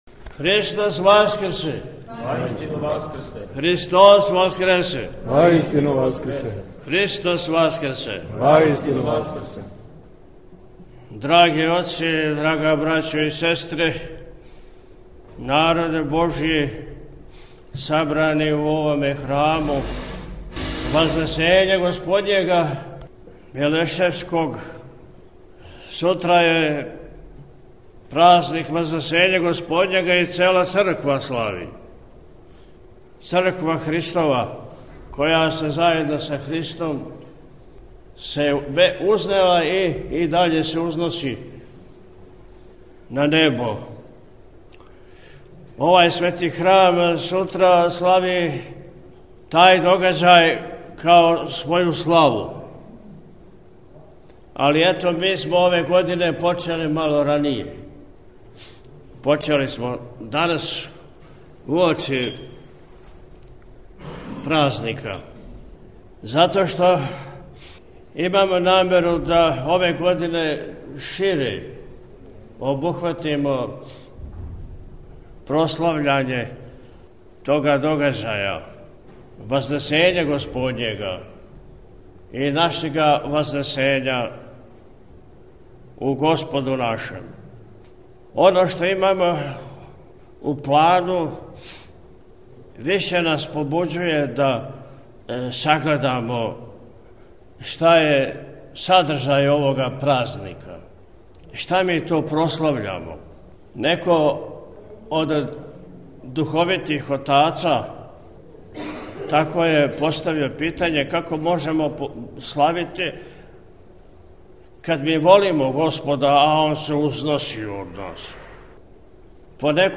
Високопреосвећени Митрополит Атанасије је у пастирској беседи, рекао: – Имамо намеру да ове године шире обухватимо прослављање Вазнесења Господњега, и нашега вазнесења у Господу нашем.